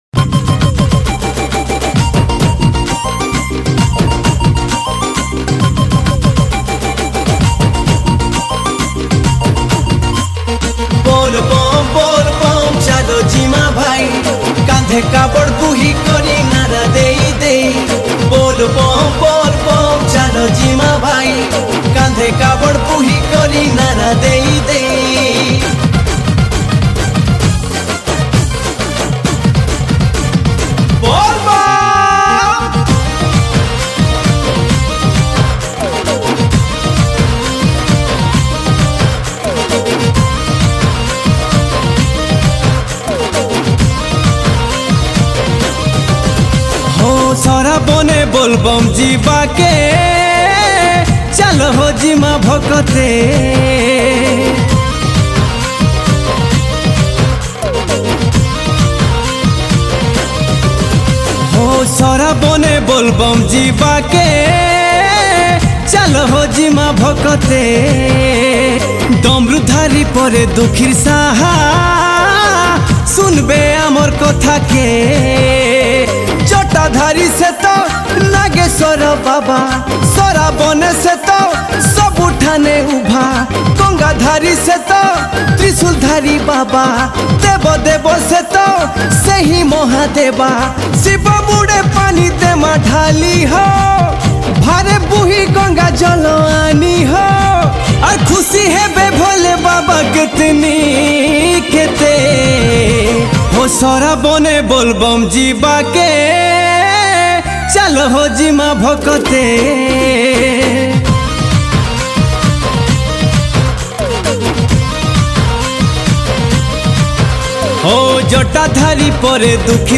Category : Bolbum Special Song